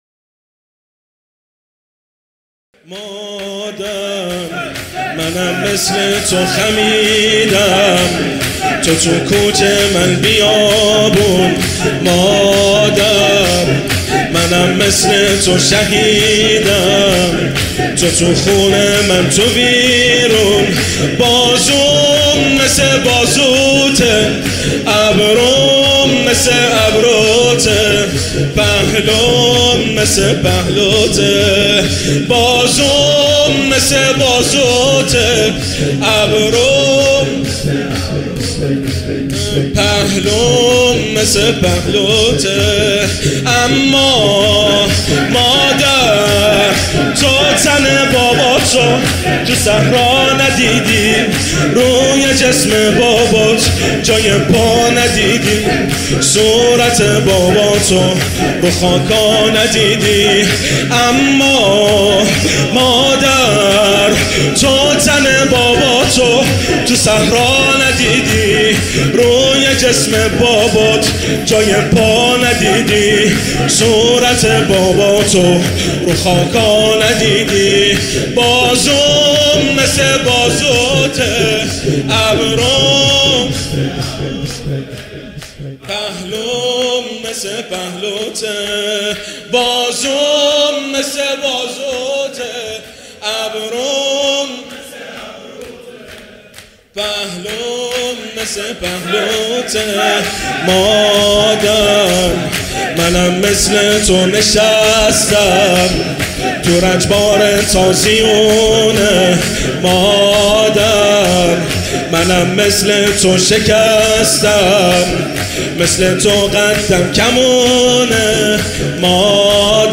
شور | مادر منم مثل تو خمیدم
مداحی
شب های شهادت حضرت فاطمه (س)
هیأت علی اکبر بحرین